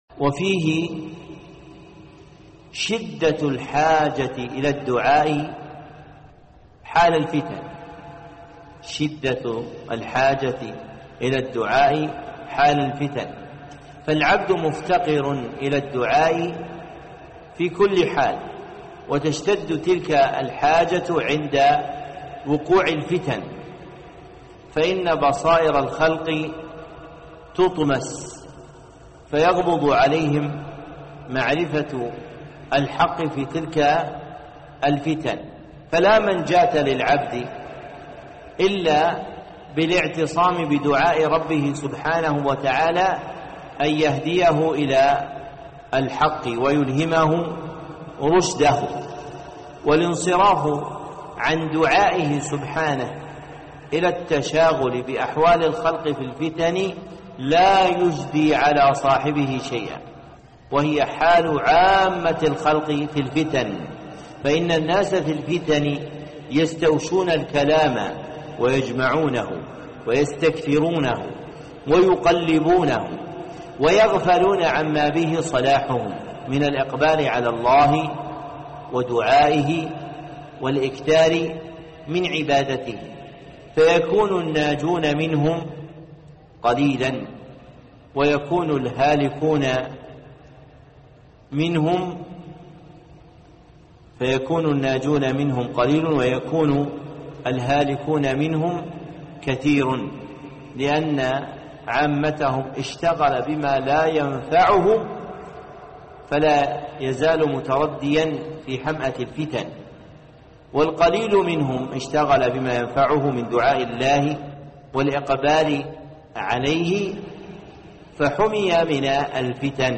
موعظة للمشتغلين بالفتن والسياسة في وسائل التواصل الإجتماعي فضيلة الشيخ صالح بن عبدالله العصيمي